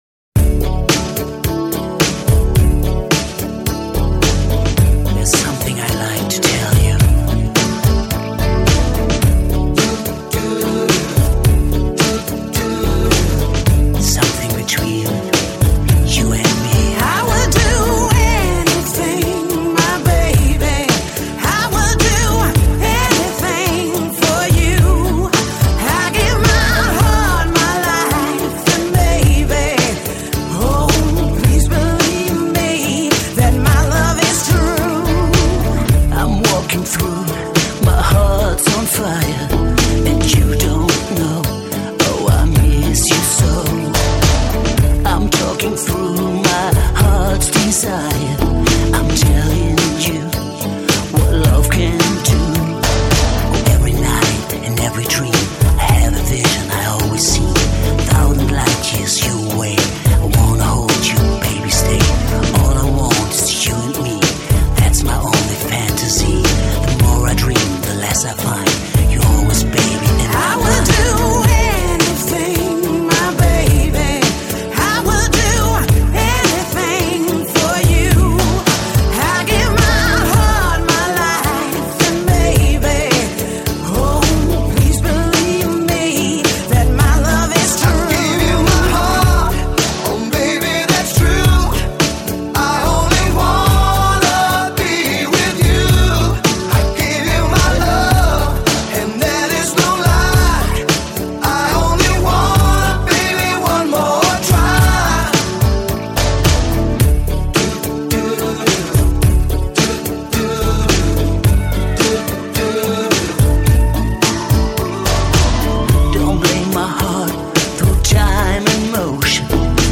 Жанр: EuroDisco